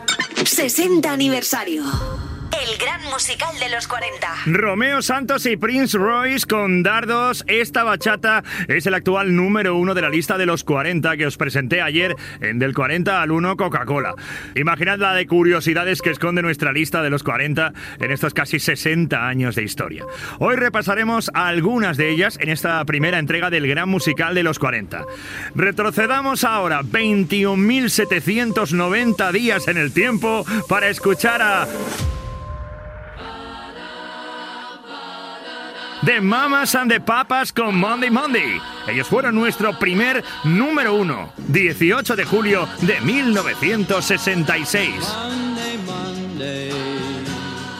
Indicatiu del programa presentació d'un tema musical del 1966.